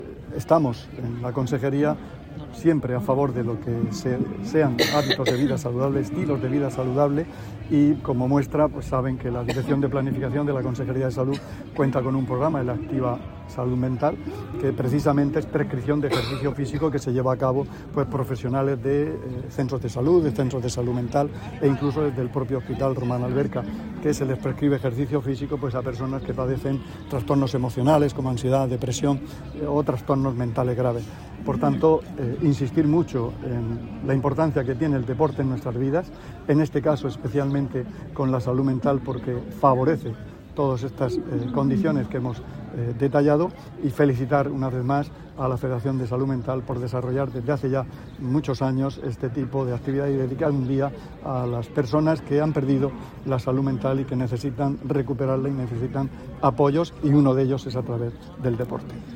Declaraciones del consejero de Salud, Juan José Pedreño, sobre el impacto del deporte en la salud mental.